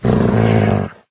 growl2.mp3